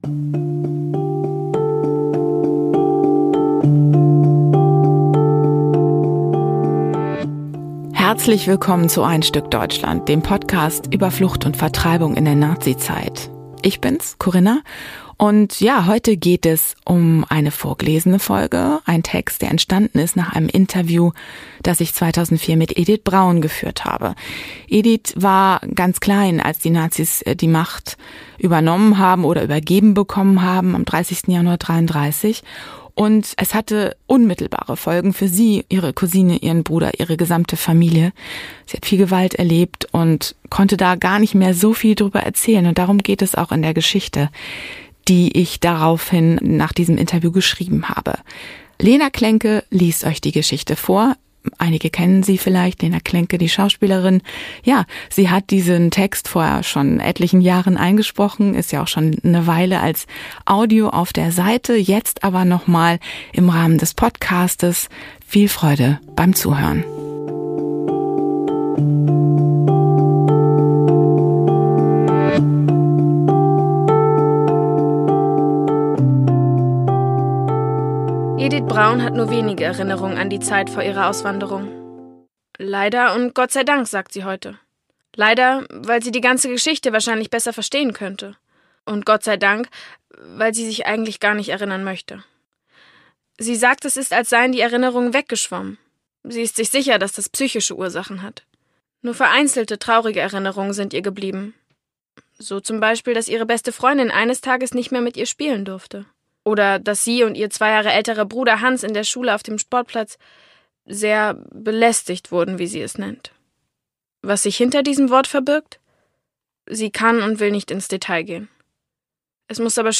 Die Schauspielerin Lena Klenke liest ihre Geschichte.